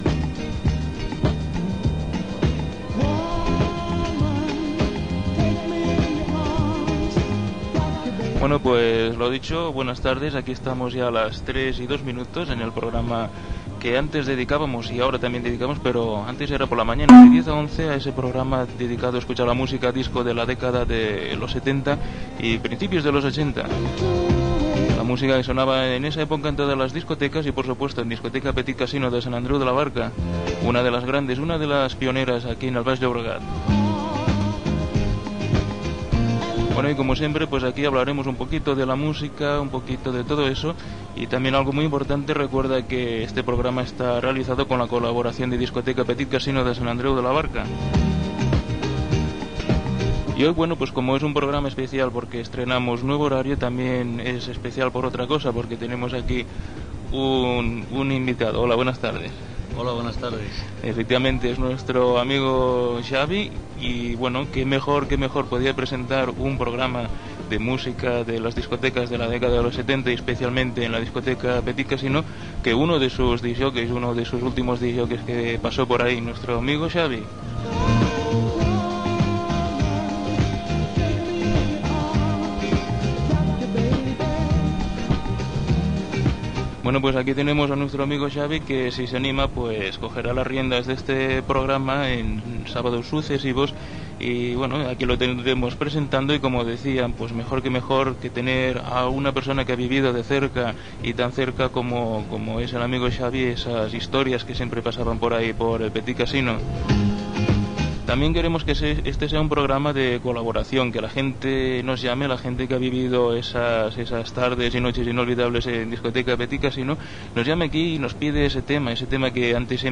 Presentació amb publicitat, invitació a la participació i conversa amb un invitat sobre la música de les disocteques
Musical
FM